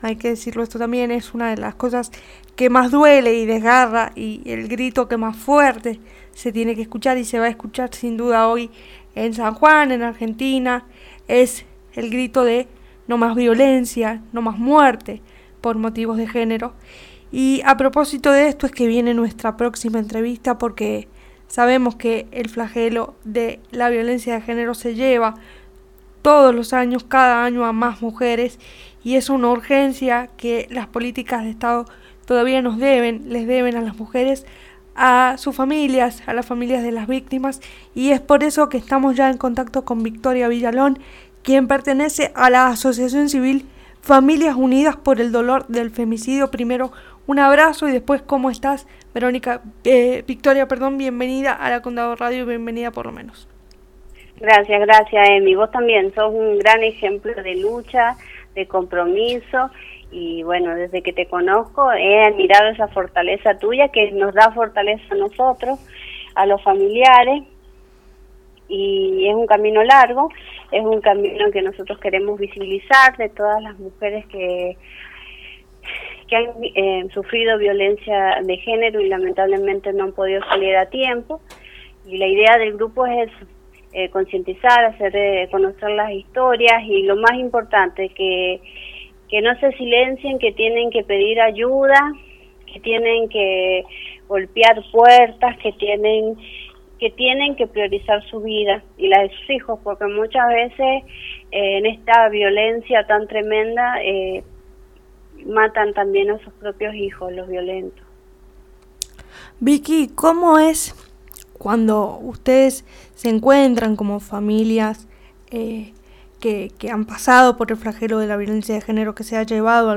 Desde la «Condado Radio» se preparo un programacion especial para acompañar la lucha de las mujeres y diversidades.
Audio de la entrevista